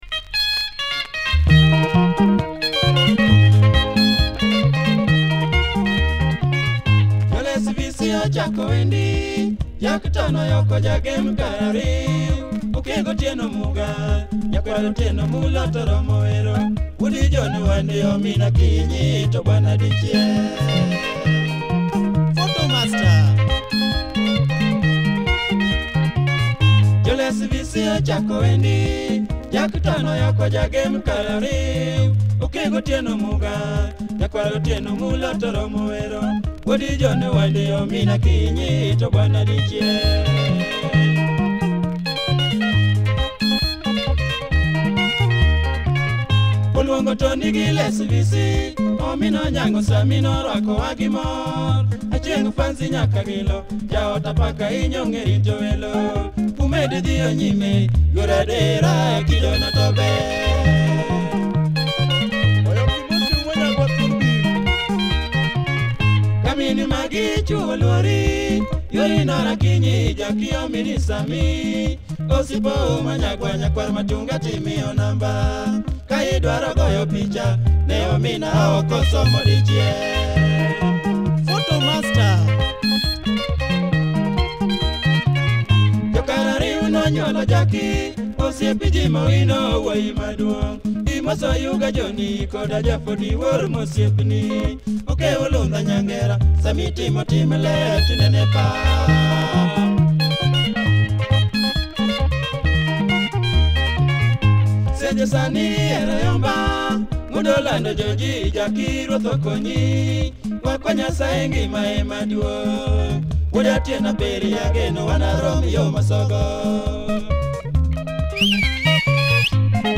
Nice LUO benga
good groove and guitar